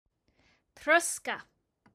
Each week, SAY IT IN IRISH features an Irish or Hiberno-English word or phrase, exploring its meaning, history and origins – with an audio recording by a native Irish speaker from Cork so you can hear how it’s pronounced.
Troscadh – pronounced roughly truska